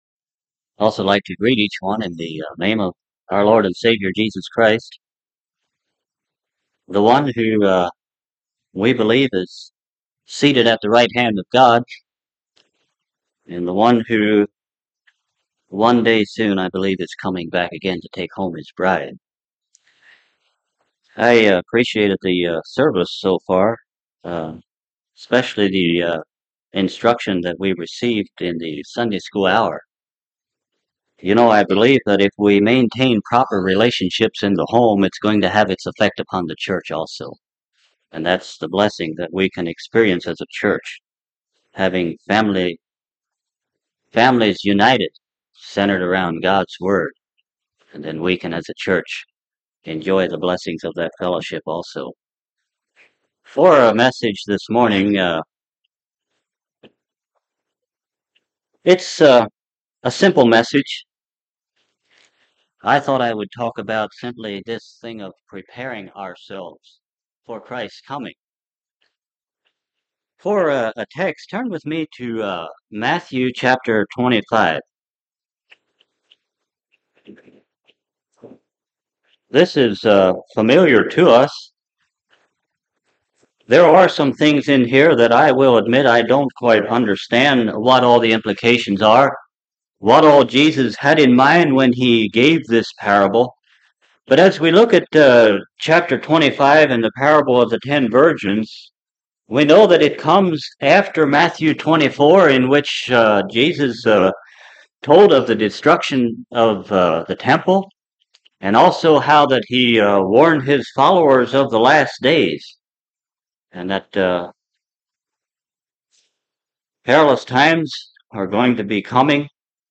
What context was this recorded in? Service Type: Sunday Morning Topics: Christ's Second Coming , Prepare for Eternity « Life of Christ